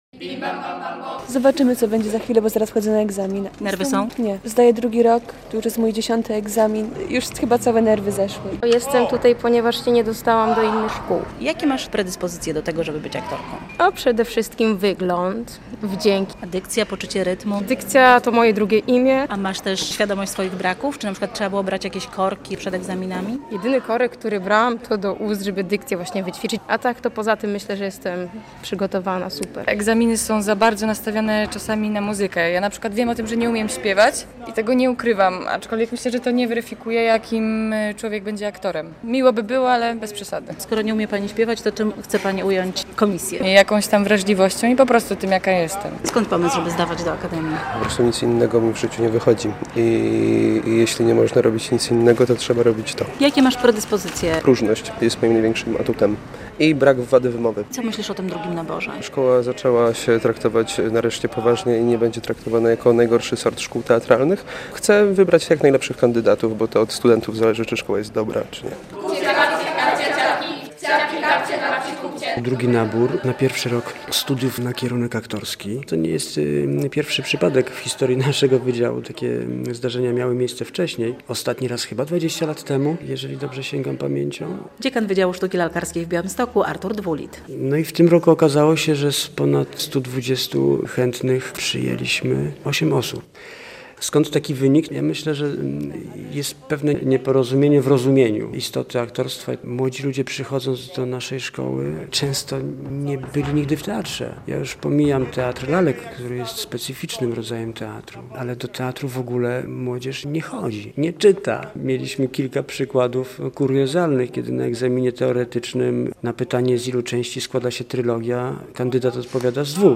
Dodatkowa rekrutacja na kierunek aktorski Wydziału Sztuki Lalkarskiej Akademii Teatralnej w Białymstoku - relacja